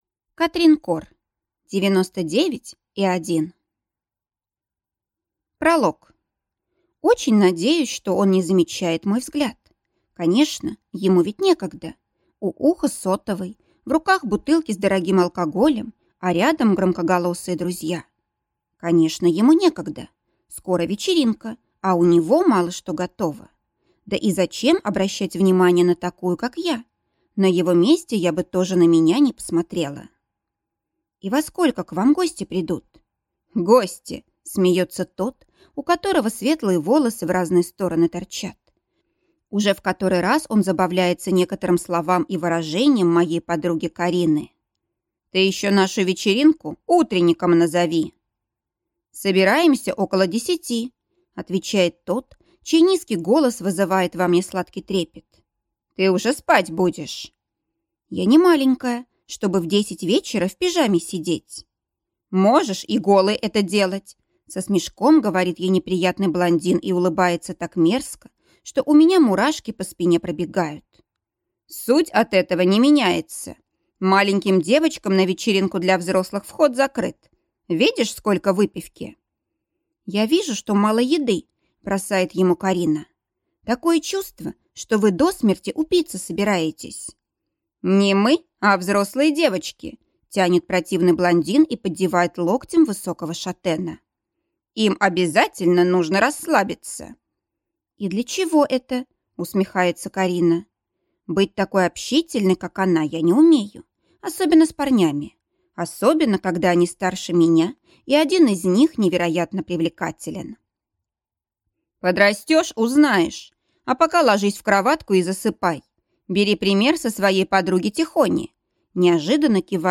Аудиокнига Девяносто девять и один | Библиотека аудиокниг